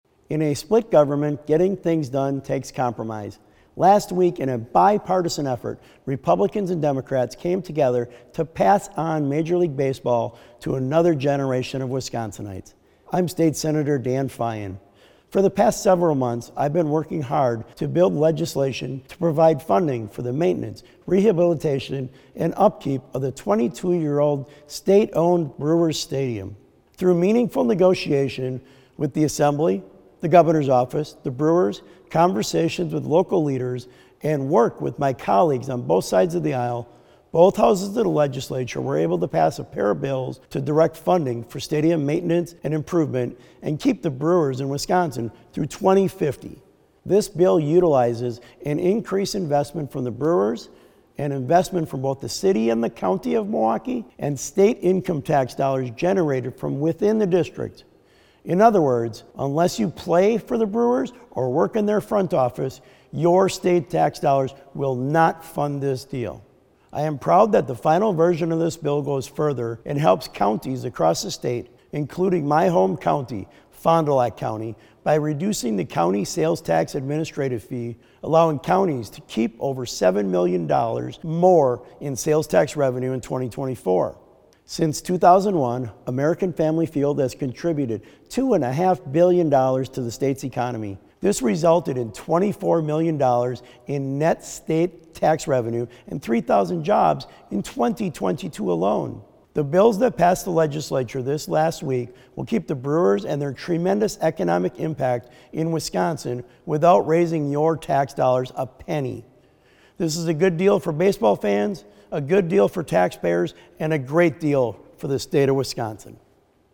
Weekly GOP radio address: Sen. Feyen says Brewers deal is a home run for Wisconsin - WisPolitics